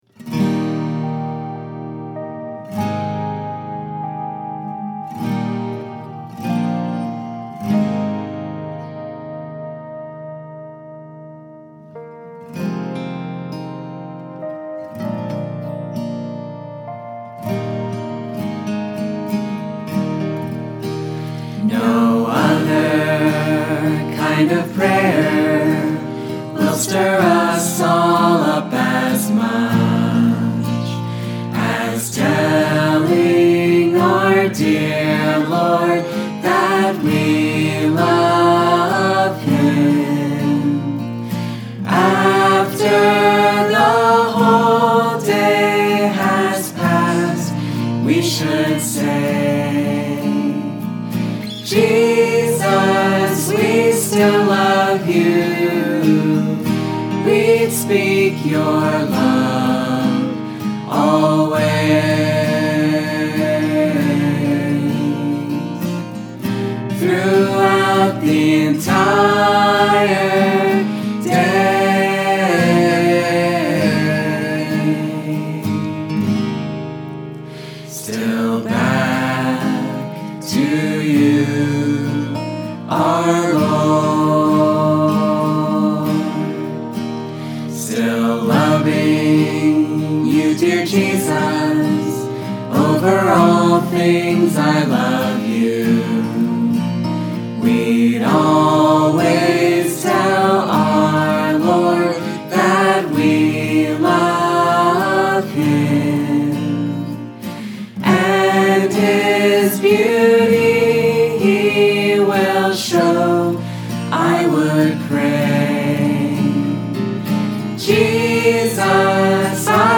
Eb Major